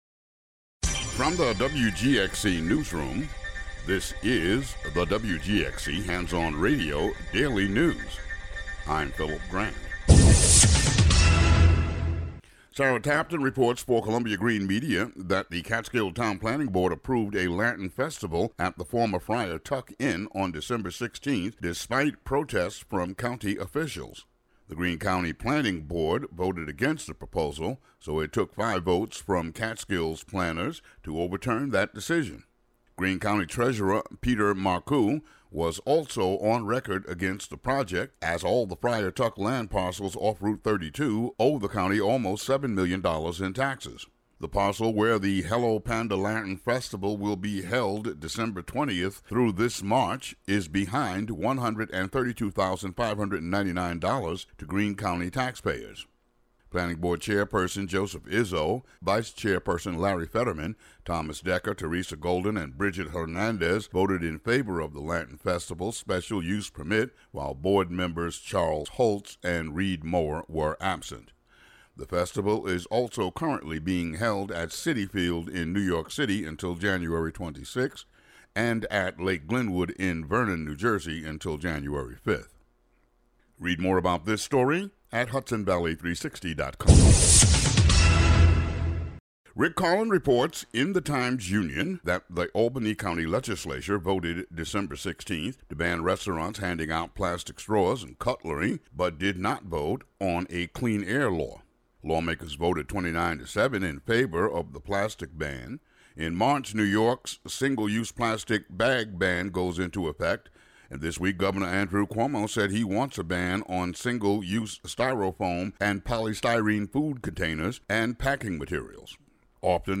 WGXC Local News Update Audio Link